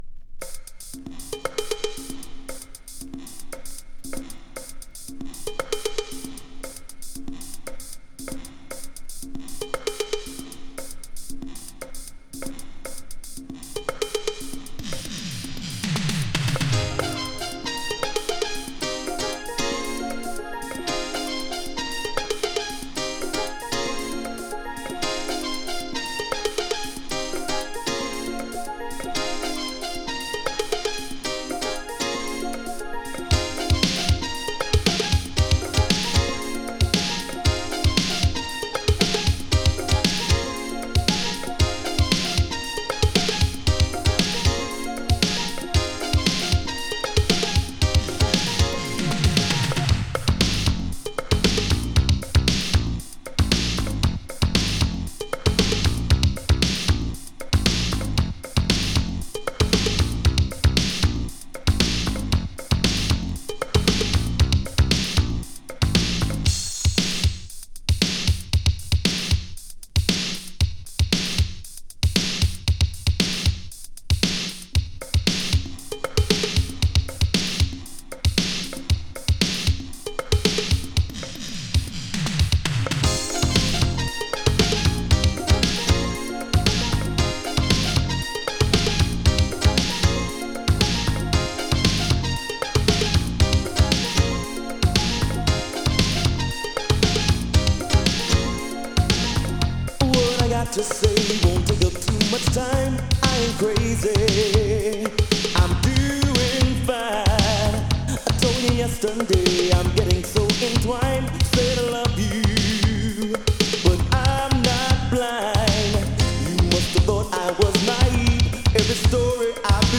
【BOOGIE】 【DISCO】
アーバン・ブギー！
エモーショナルなヴォーカルとラテン・フリースタイルなサウンドが印象的なアーバン・ブギー！